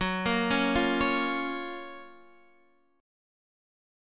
GbM7 Chord